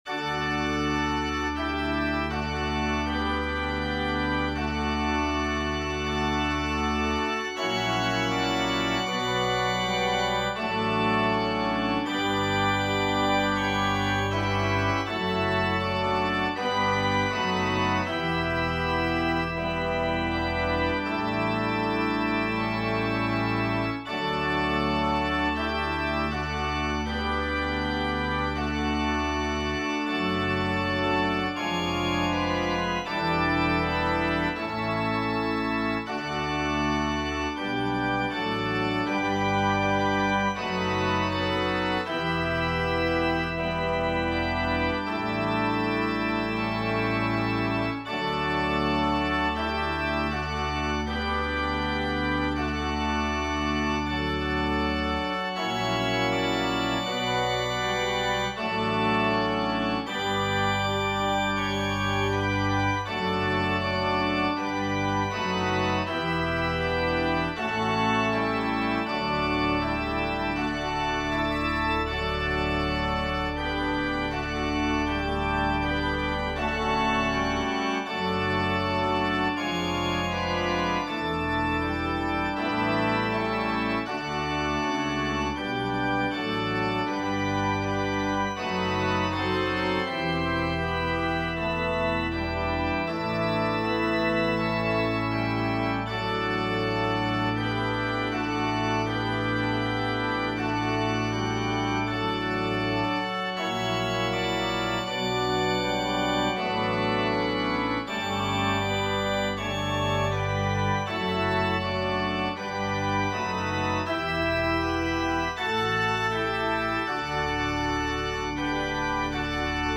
Voicing/Instrumentation: Organ/Organ Accompaniment